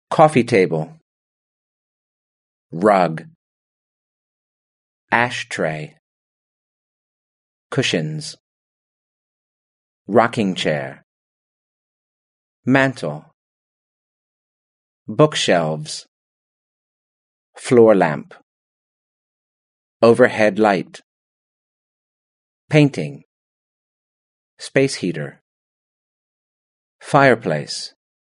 Listen to how these words are pronounced.